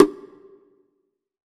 CONGA 31.wav